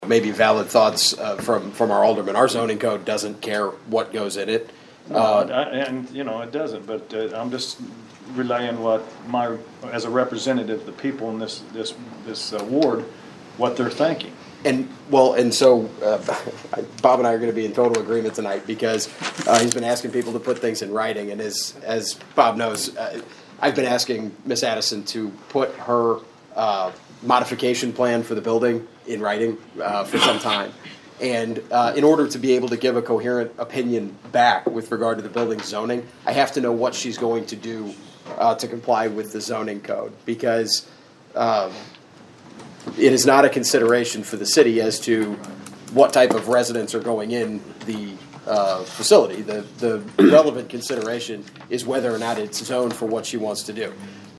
There was a long discussion at Monday’s Vandalia City Council meeting on the possible sale of the former First Baptist Church building in downtown Vandalia.